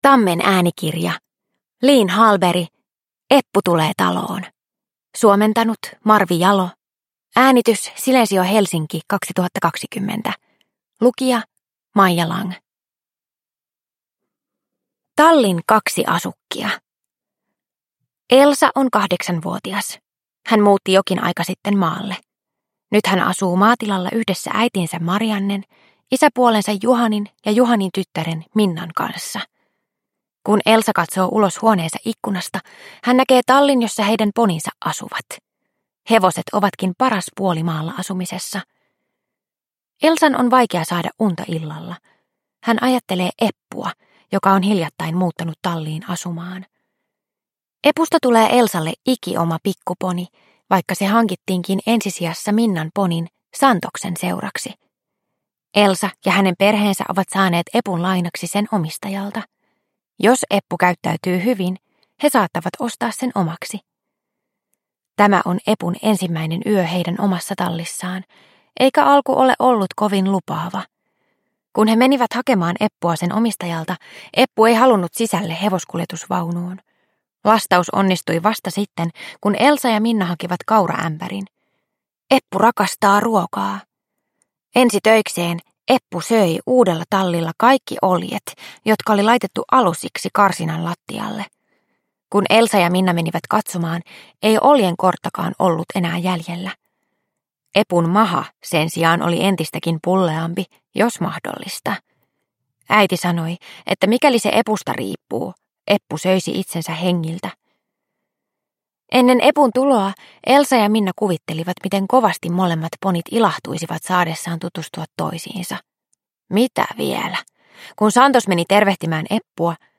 Eppu tulee taloon – Ljudbok – Laddas ner